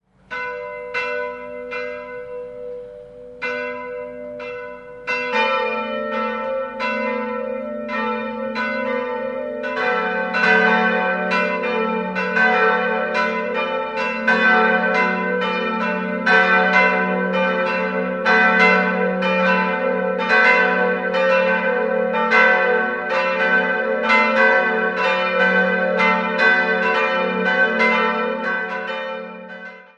3-stimmiges TeDeum-Geläute: fis'-a'-h' Martinsglocke fis' 724 kg
Marienglocke a' 456 kg
Josefsglocke h' 284 kg